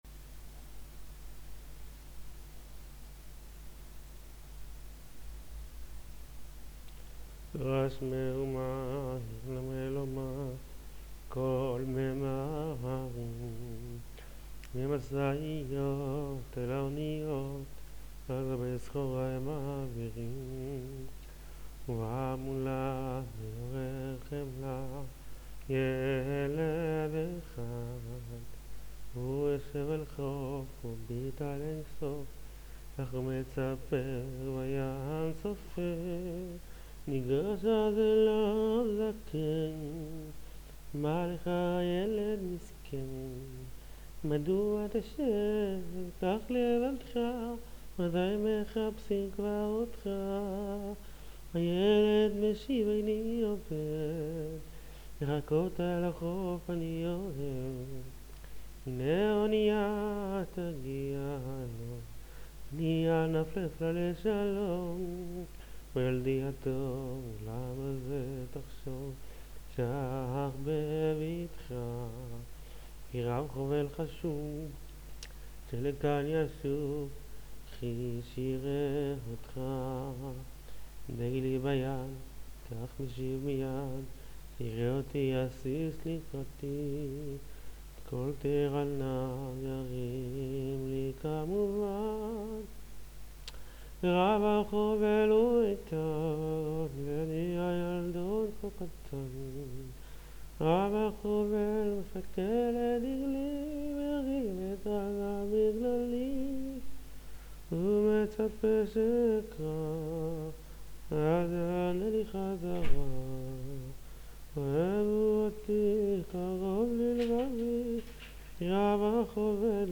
דוגמה להקלטה מפלאפון שמישהו שלח לי